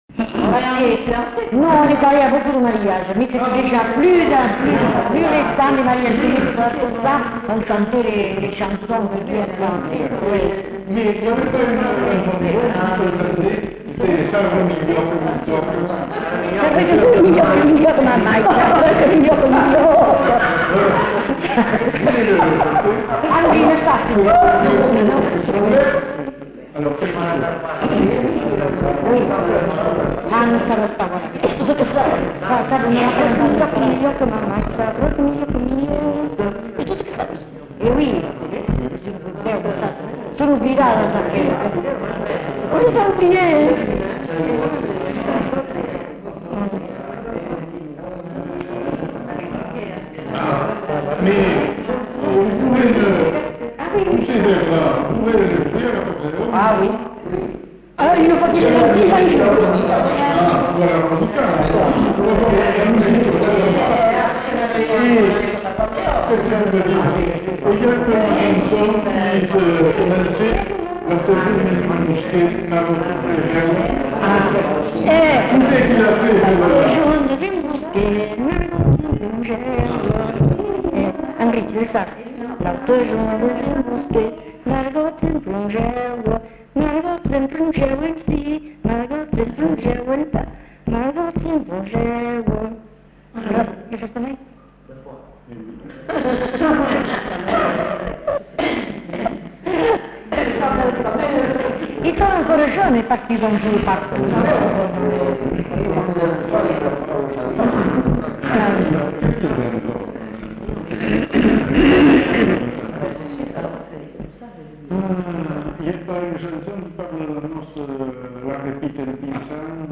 Lieu : Cazalis
Genre : témoignage thématique